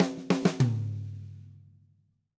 Drumset Fill 04.wav